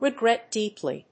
regret+deeply.mp3